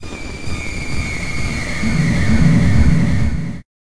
breathe3.ogg